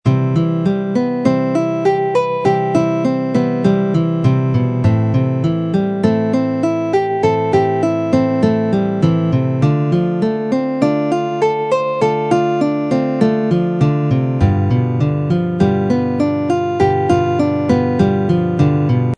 For instance: the first chord is a C major for two bars, the lead is a C major seventh arpeggio played over the top of these two bars. I have had to very slightly alter some patterns to fit within the two bar rhythm. let's start by learning the rhythm, it's a simple I-VI-II-V progression in the key of C major.
Arpeggio exercise chart – Rhythm & lead
arpeggio-exercise-rhythm-and-lead.mp3